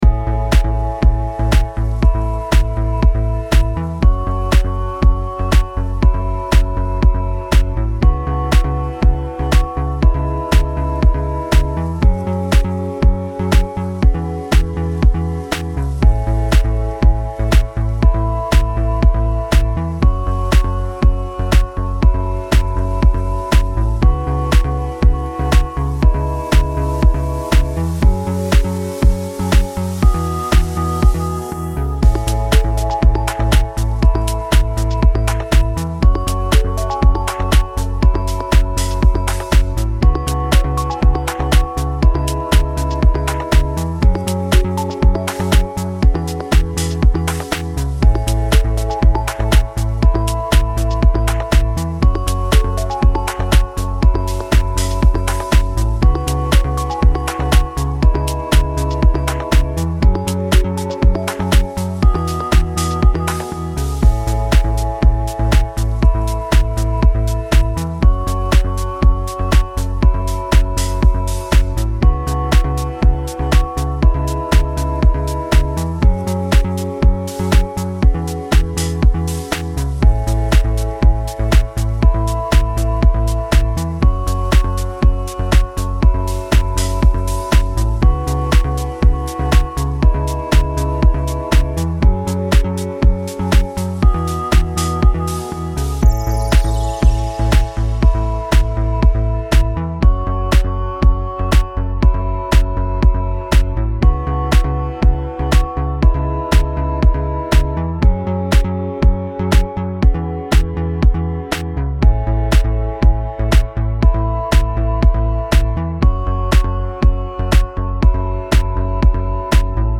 Musique Chill Attente 4 minutes